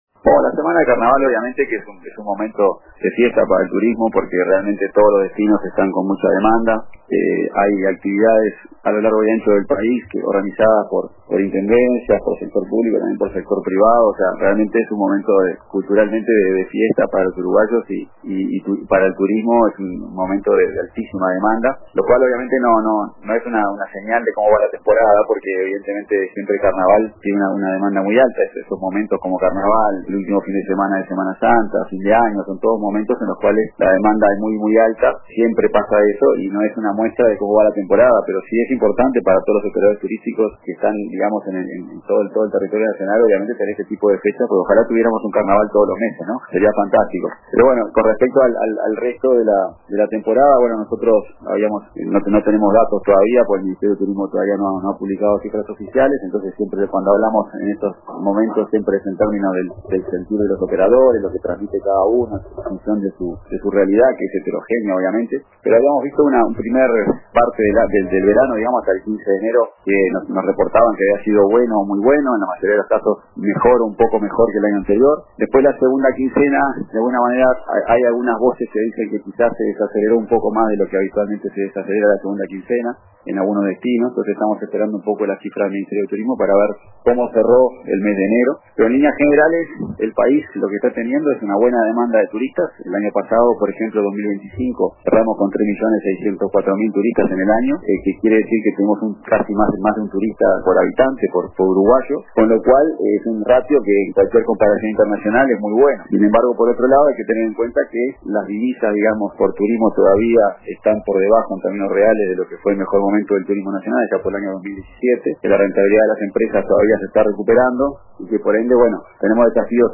Entrevistado en el programa Radio con Todos